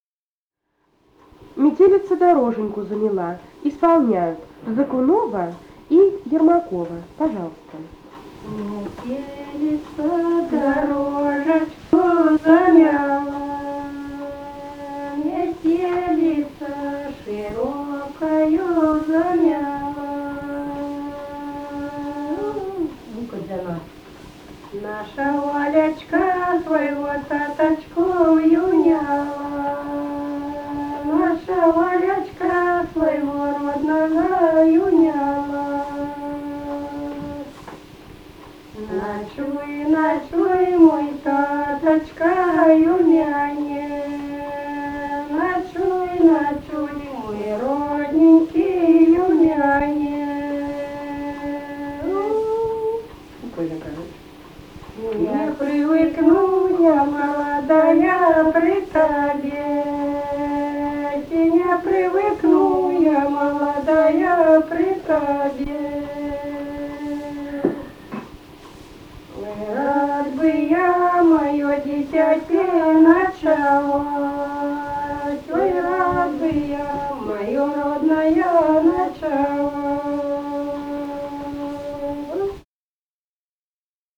Записали участники экспедиции